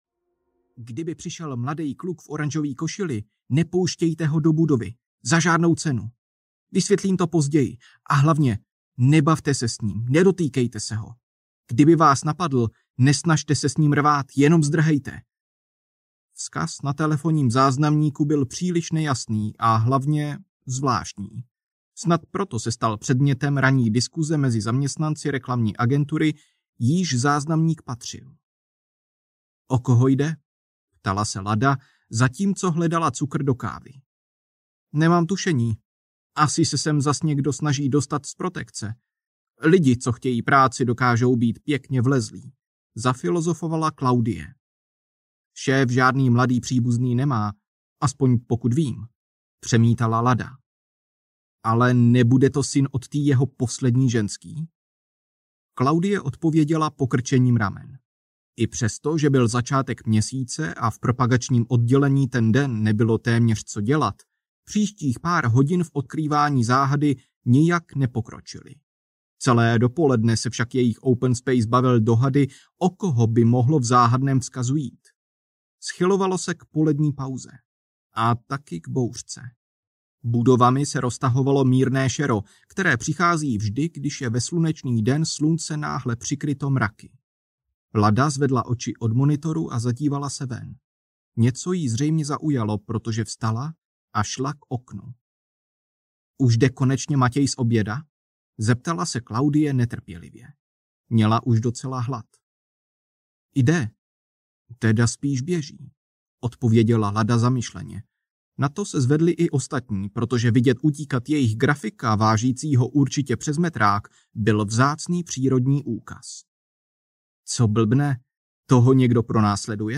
SEIF audiokniha
Ukázka z knihy